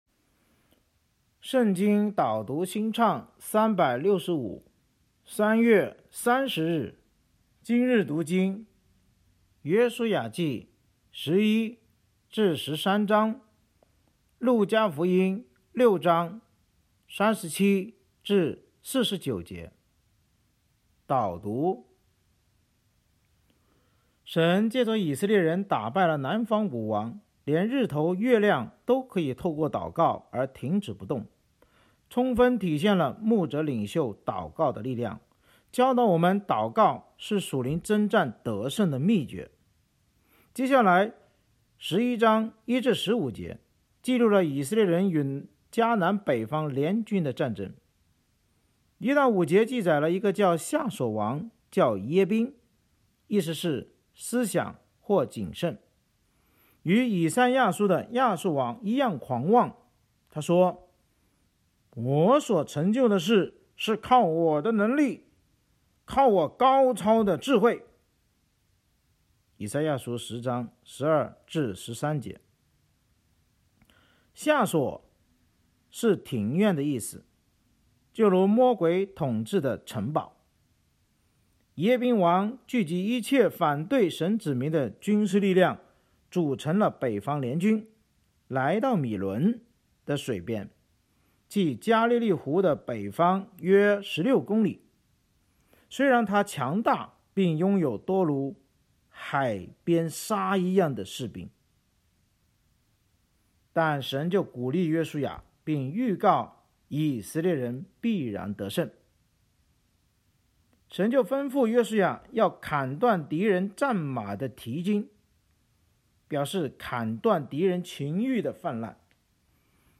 圣经导读&经文朗读 – 03月30日（音频+文字+新歌）